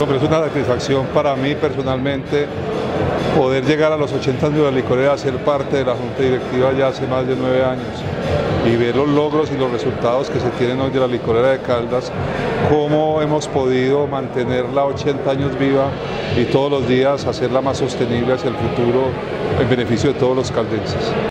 El pasado jueves se llevó a cabo en la Gobernación de Caldas un acto de conmemoración de las bodas de roble (80 años) de la empresa más importante del departamento.